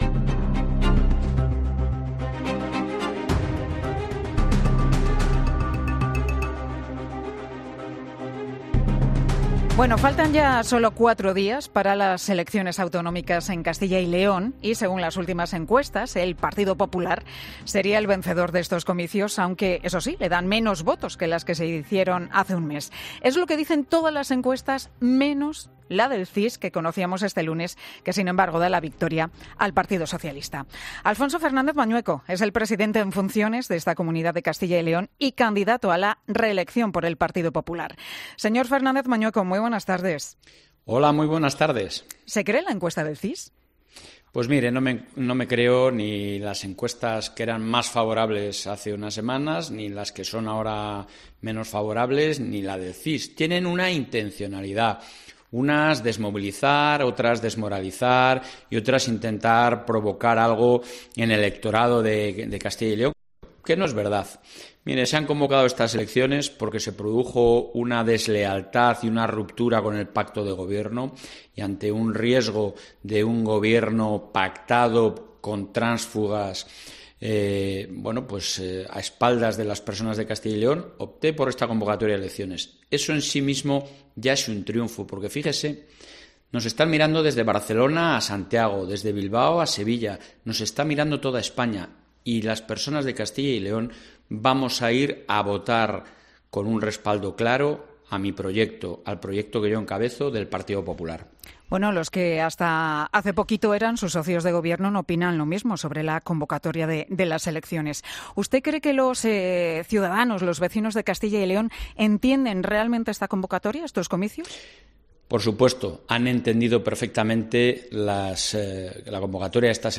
Pilar García Muñiz entrevista en Mediodía COPE al presidente en funciones de Castilla y León: "Son unas elecciones a las que mira toda España".